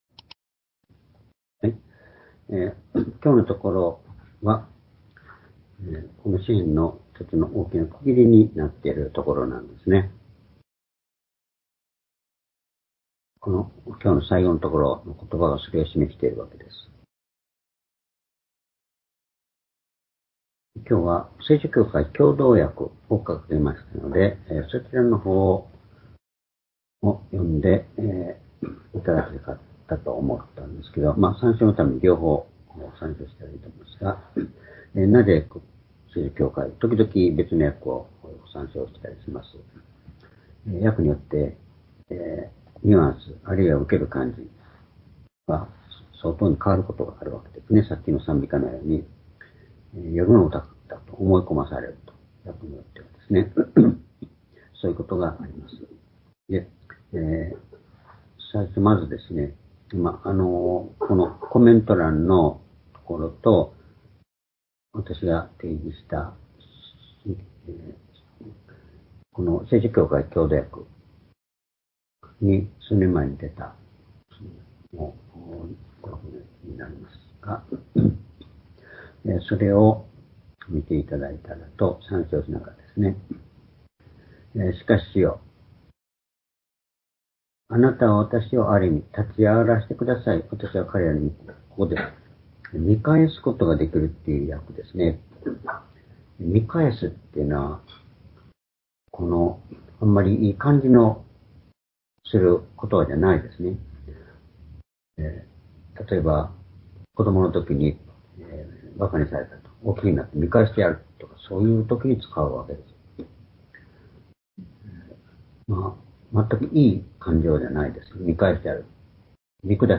（主日・夕拝）礼拝日時 2024年5月7日(夕拝) 聖書講話箇所 「主の前に永遠に立たせてくださる神の愛」 詩編41編11～14節 ※視聴できない場合は をクリックしてください。